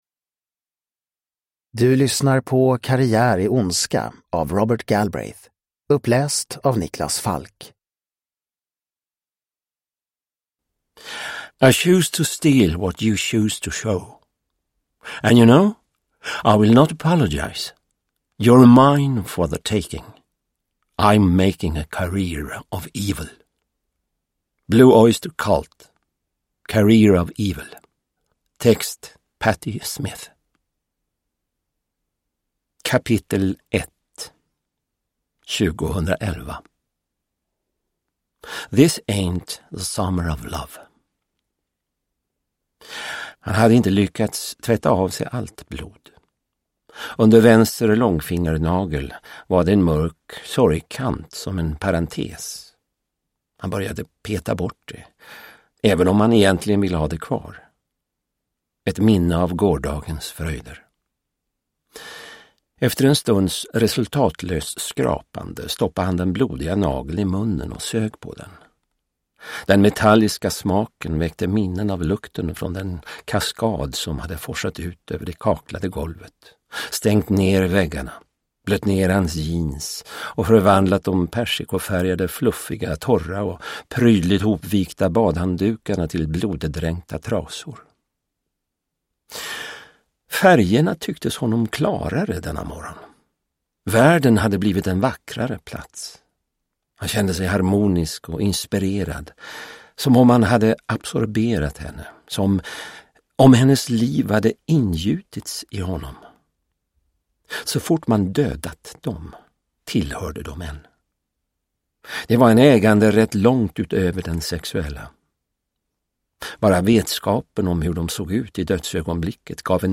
Karriär i ondska (ljudbok) av Robert Galbraith